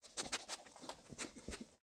Minecraft Version Minecraft Version 1.21.5 Latest Release | Latest Snapshot 1.21.5 / assets / minecraft / sounds / mob / wolf / sad / panting.ogg Compare With Compare With Latest Release | Latest Snapshot
panting.ogg